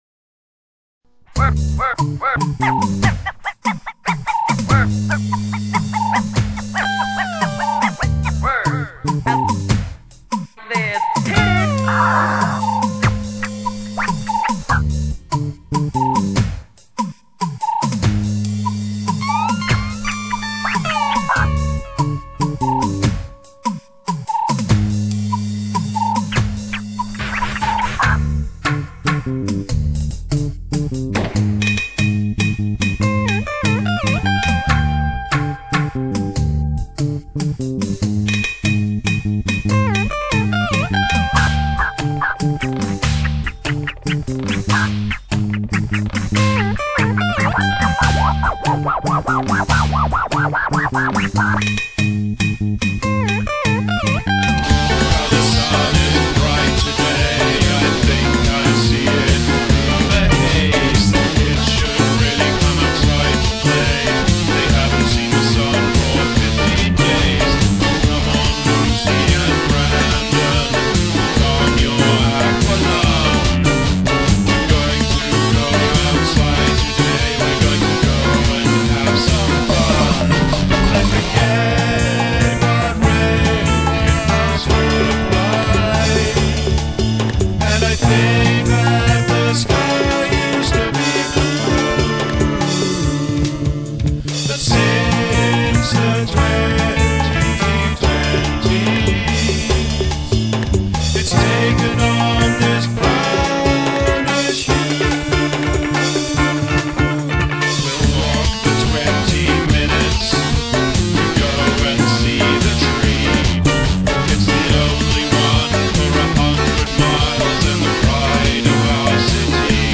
Scratching